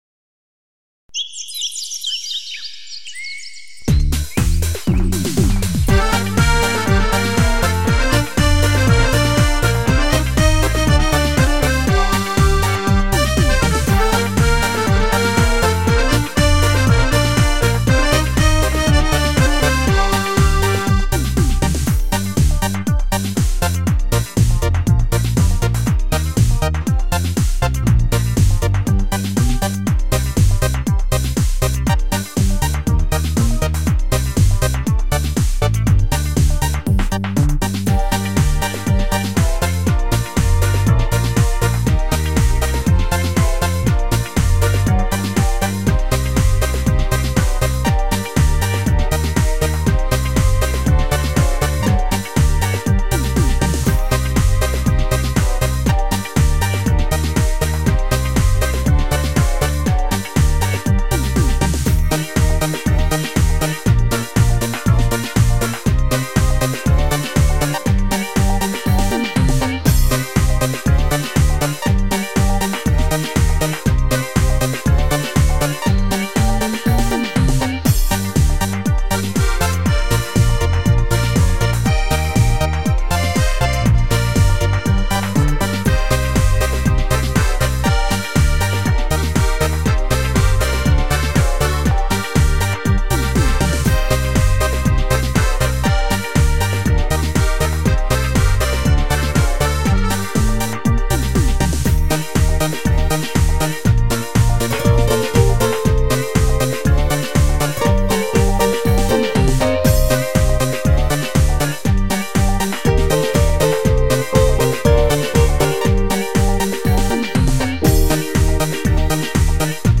Фонограма-мінус (mp3, 128 kbps)